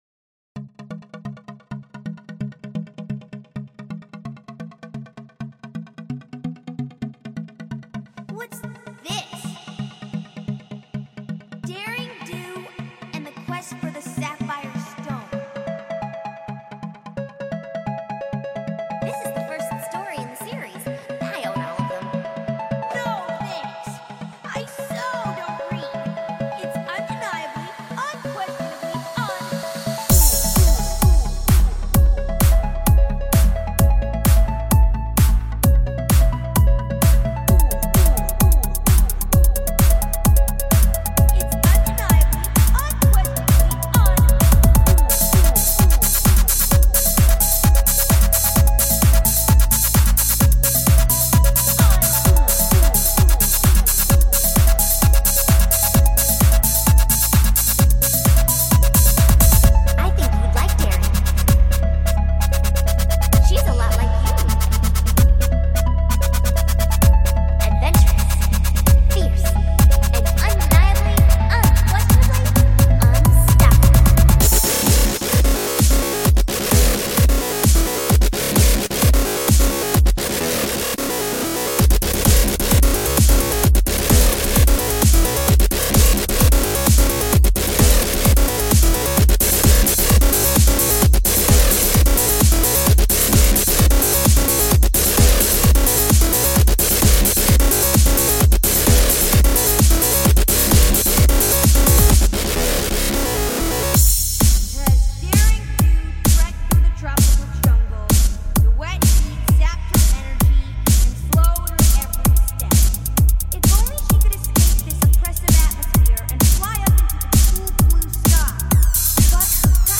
Auto-tuned Rainbow Glitches.